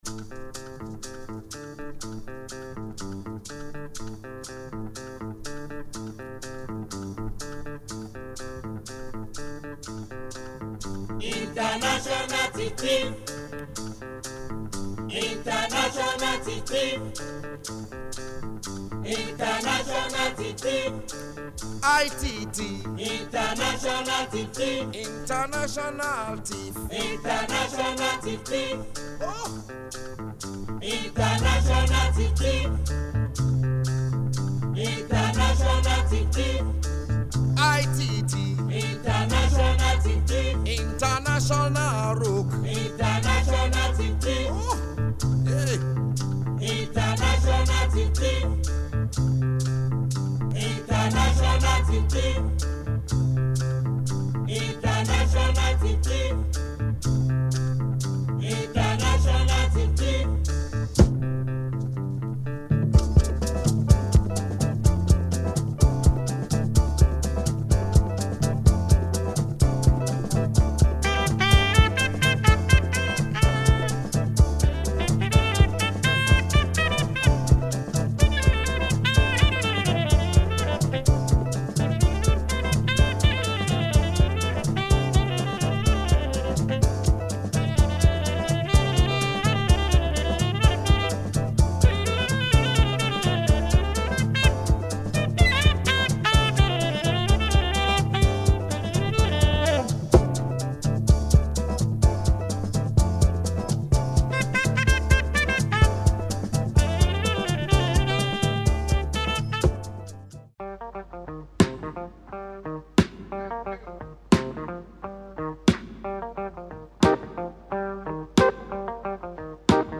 Classic afrobeat LP
Very groovy !